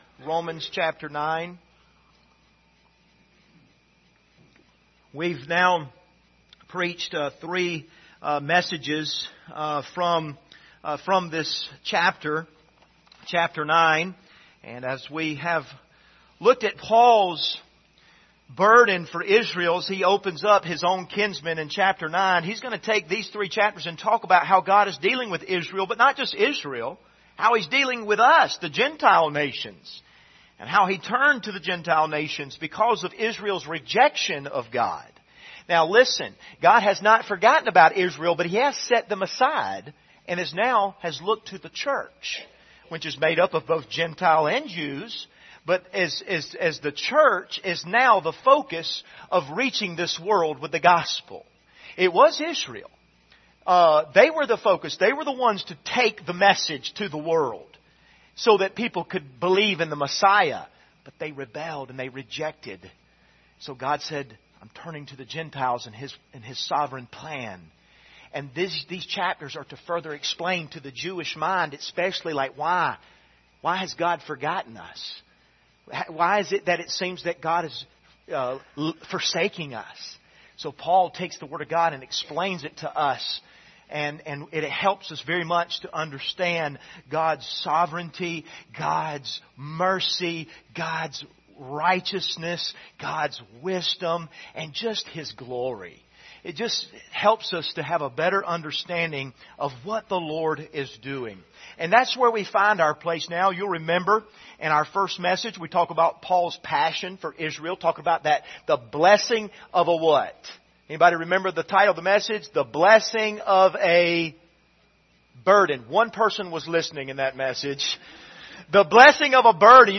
Passage: Romans 9:14-24 Service Type: Sunday Morning